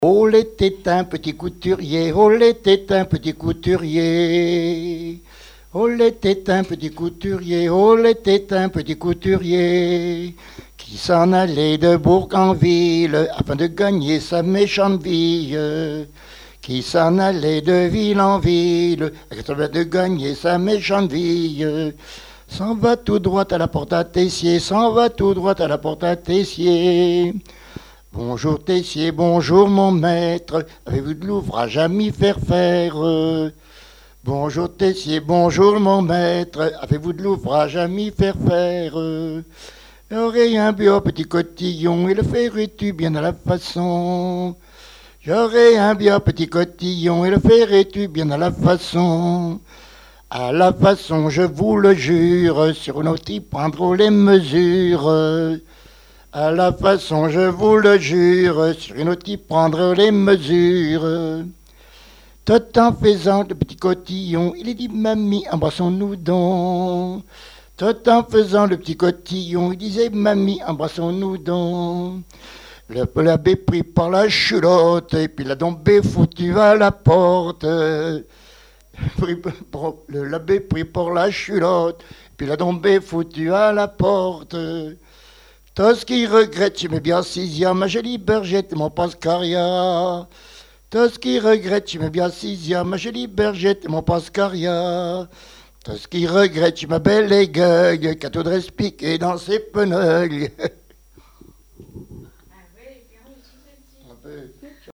Chants brefs - A danser
danse : scottich sept pas ;
Pièce musicale inédite